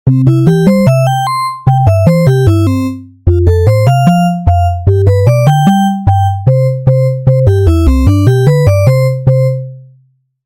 ポップでコミカルな感じ。
BPM150
コミカル ファンファーレ 勝利 明るい 軽快